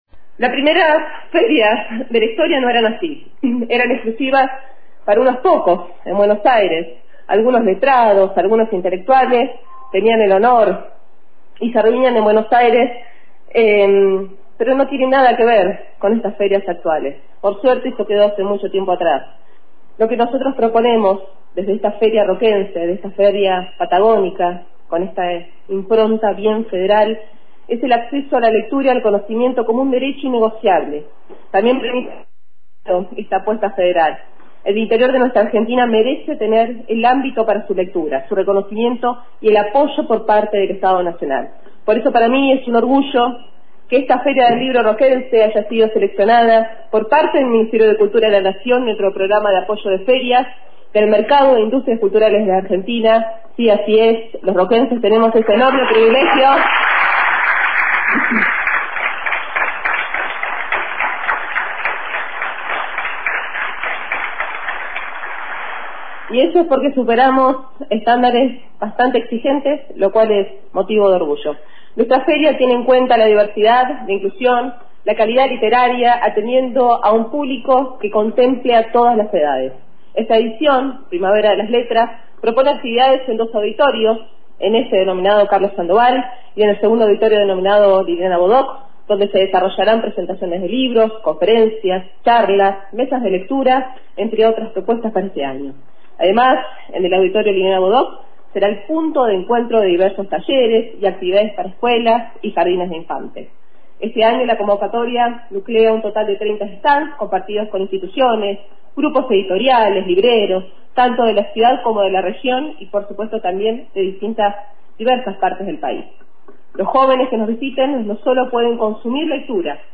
Con una gran variedad de actividades se inició la llamada “Primavera de las Letras” en la plaza principal de la ciudad.
La inauguración estuvo presidida por la intendenta María Emilia Soria. A continuación, parte de su discurso.